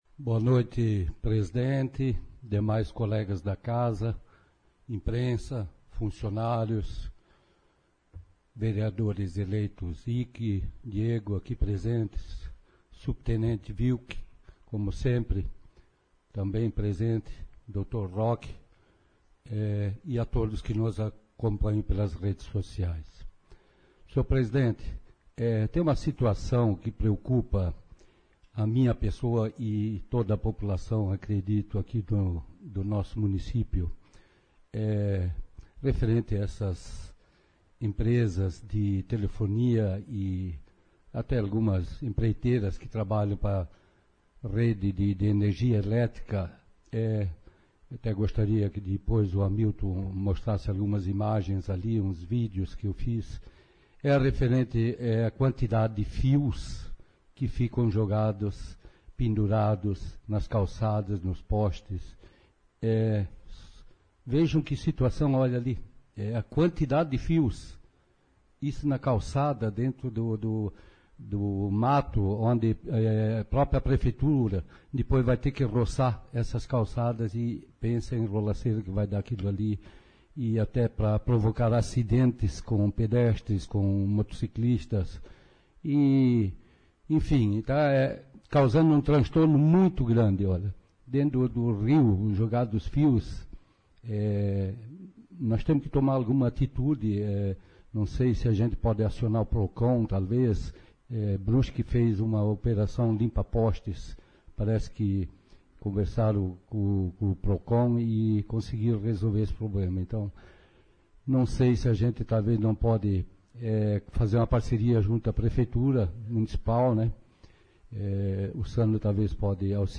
Câmara Municipal realizou sessão ordinária na terça-feira, 15